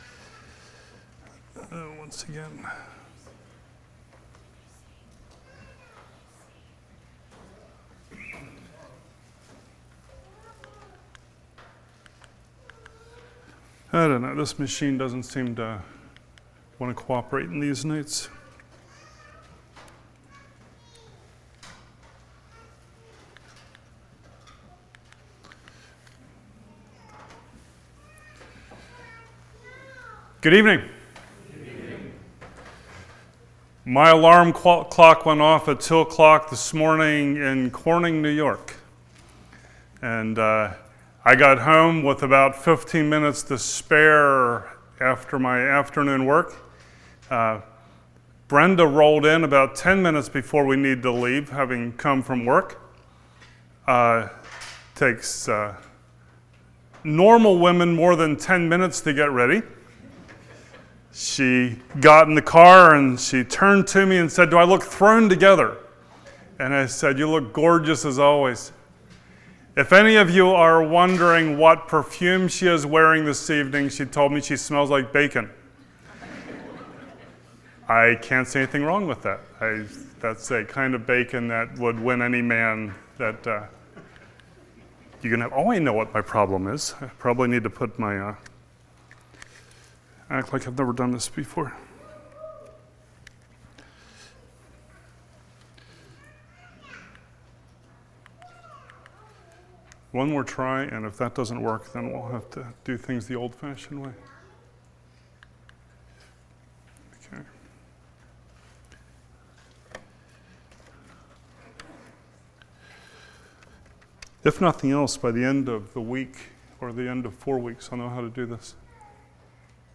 Acts 15:22-32 Service Type: Winter Bible Study What are fences for?